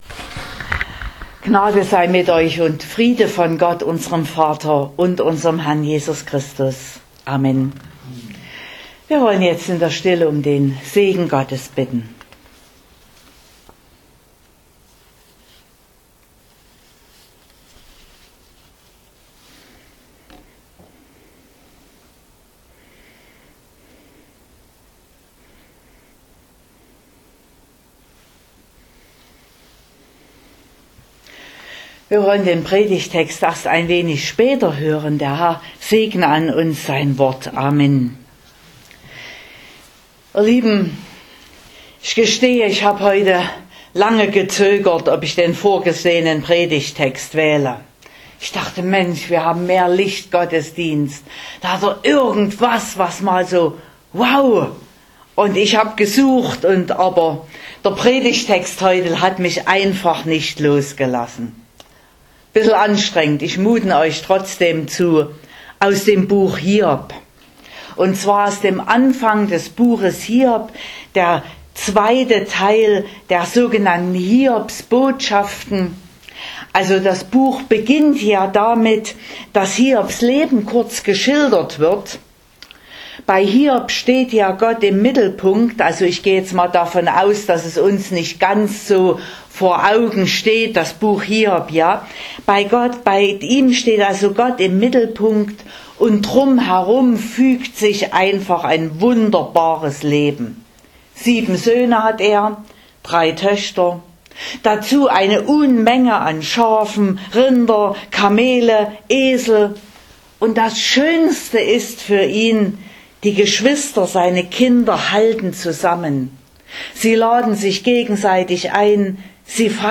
26.02.2023 – Mehr-Licht-Gottesdienst
Predigt (Audio): 2023-02-26_Hiobs_Beispiel.mp3 (33,9 MB)